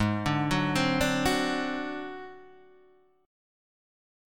G# 7th Flat 5th